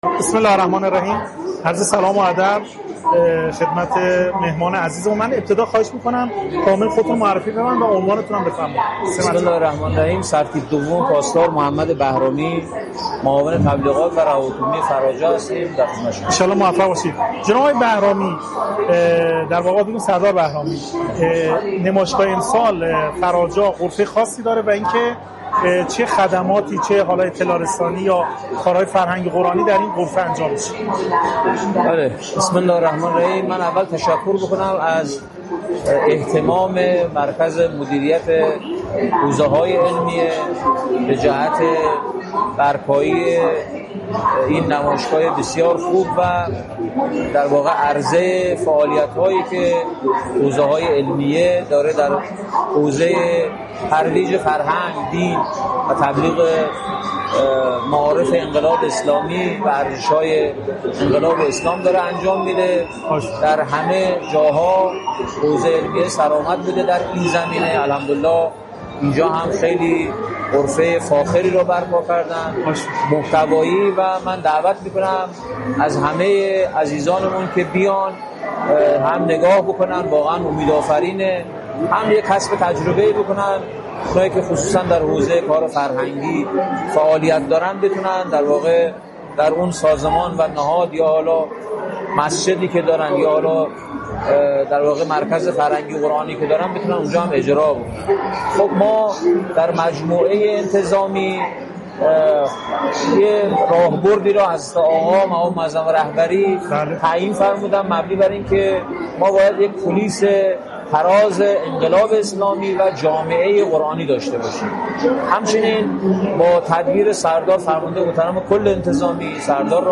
سردار محمد بهرامی در غرفه خبرگزاری حوزه در سی و سومین نمایشگاه بین المللی قرآن کریم در گفت‌وگو با خبرنگار خبرگزاری حوزه با قدردانی از حوزه‌های علمیه به‌جهت برپایی بخش حوزوی نمایشگاه قرآن و عرضه فعالیت‌های فرهنگی و دینی، اظهار داشت: حوزه‌های علمیه همواره در ترویج فرهنگ دین، تبلیغ معارف انقلاب اسلامی و ارزش‌های انقلاب پیشتاز بوده‌اند و این نمایشگاه نیز جلوه‌ای فاخر و محتوایی از این تلاش‌هاست.